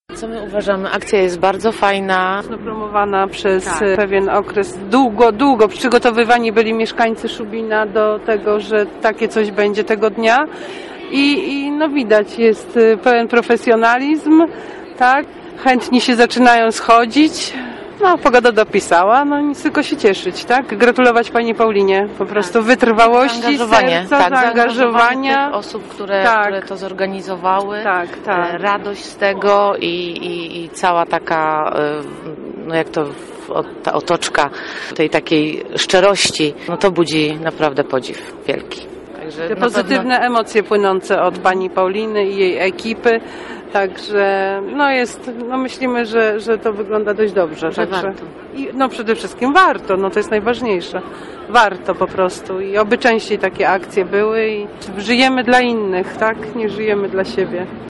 Od godziny 9.00 na rynku w Szubinie stanął ambulans do poboru krwi z Regionalnego Centrum Krwiodawstwa i Krwiolecznictwa z Bydgoszczy.